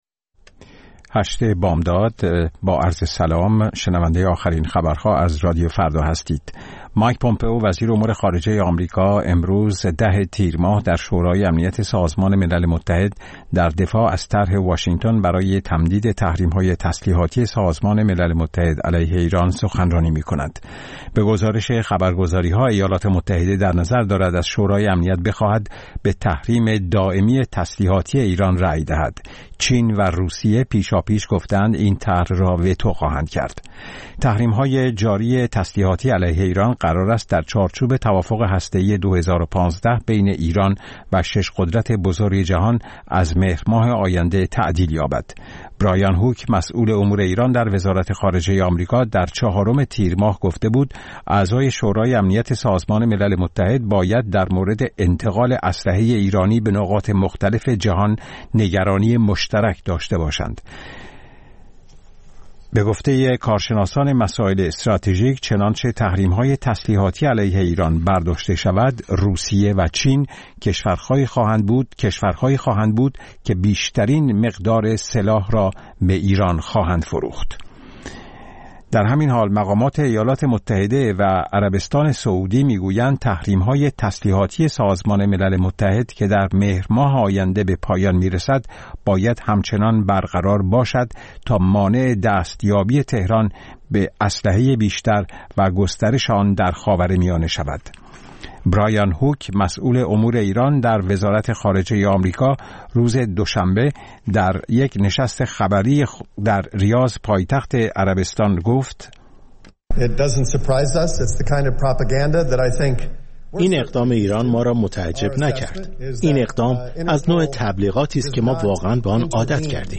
اخبار رادیو فردا، ساعت ۸:۰۰